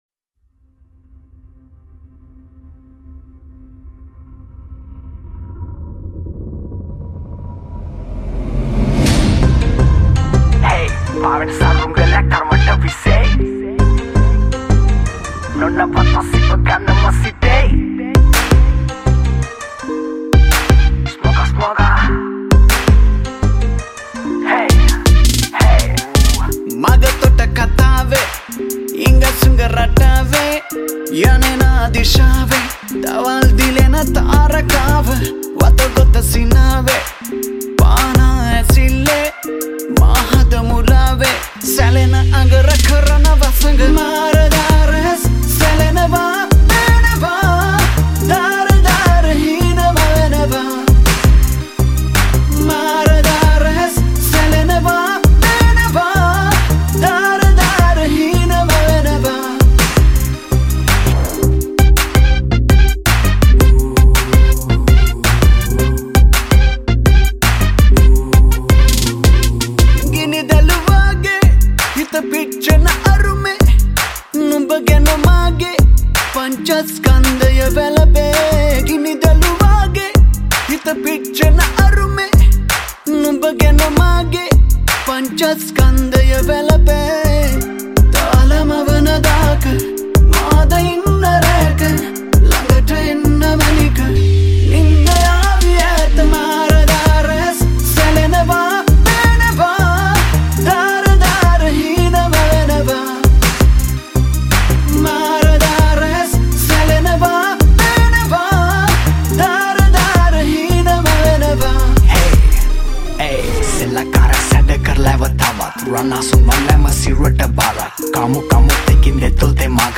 High quality Sri Lankan remix MP3 (3).
remix